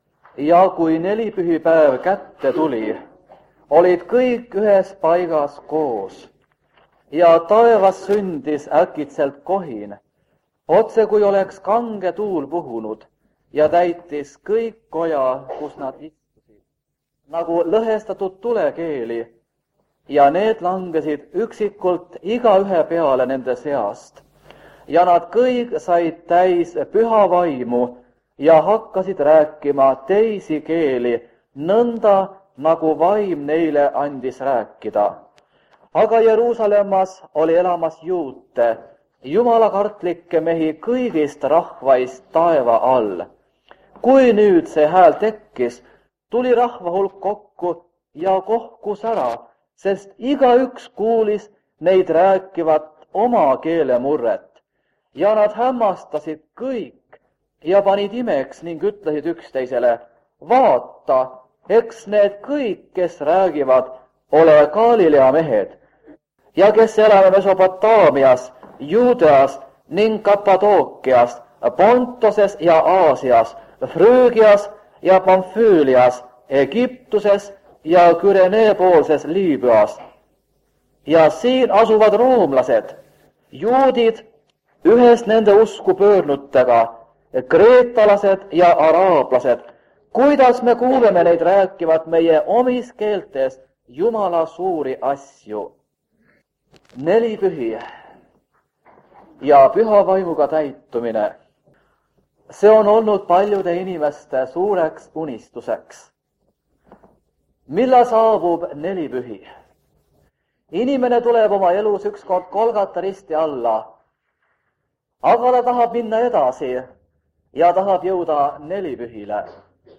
Jutlus vanalt lintmaki lindilt.